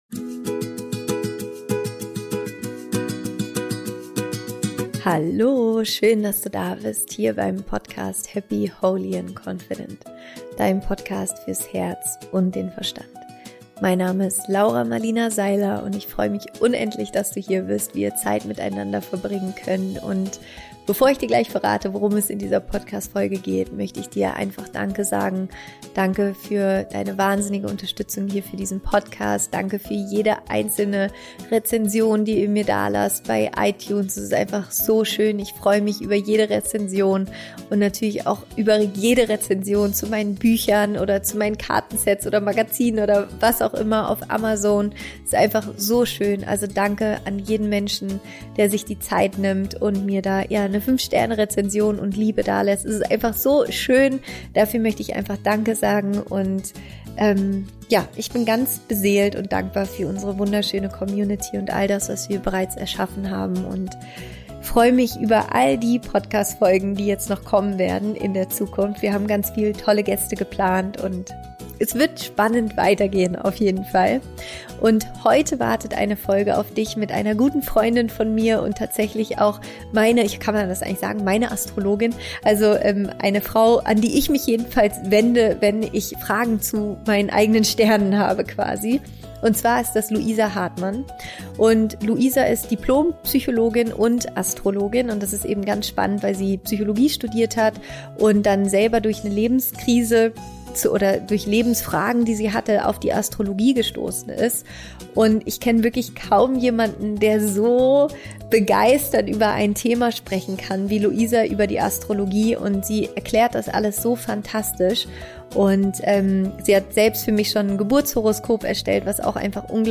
Im Interview sprechen wir darüber, was Astrologie genau ist und was die Sternenkonstellationen zum Zeitpunkt unserer Geburt, alles über uns verraten.